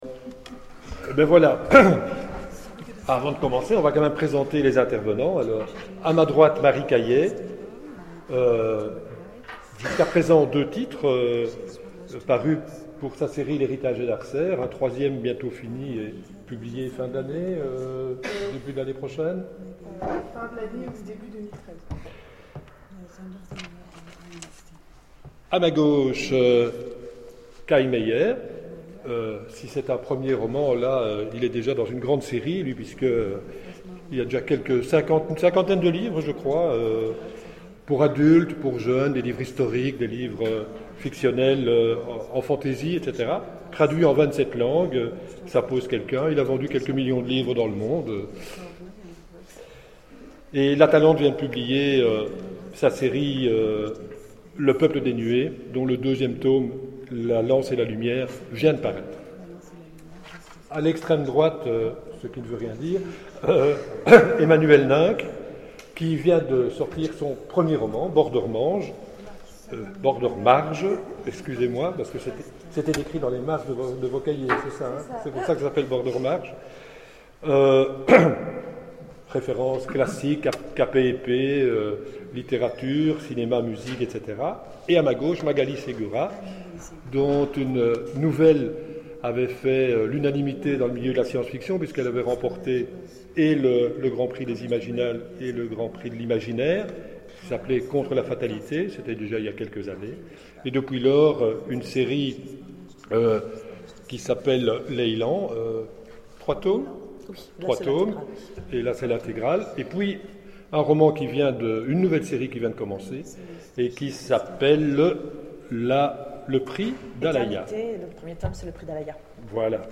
Imaginales 2012 : Conférence Amour, souffrance, violence, peut-on parler de tout aux adolescents ?